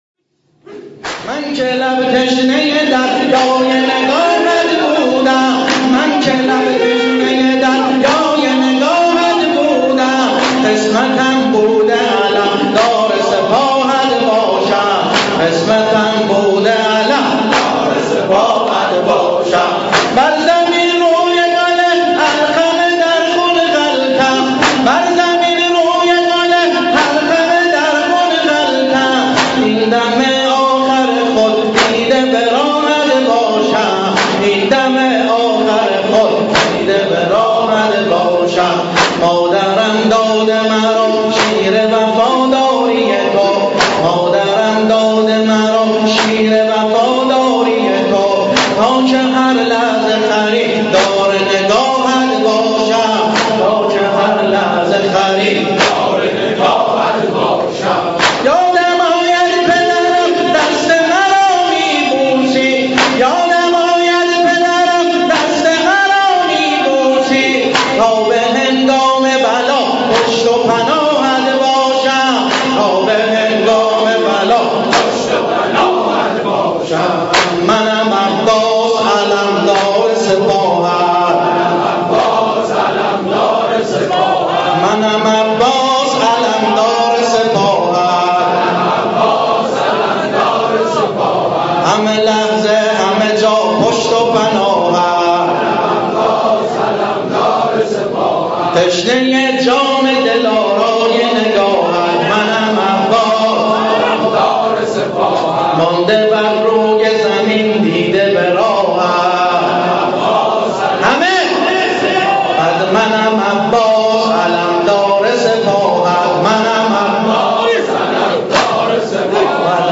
دانلود متن و سبک نوحه حضرت ابوالفضل -( من که لب تشنة دریای نگاهت بودم )